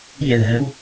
keyword-spotting
speech-commands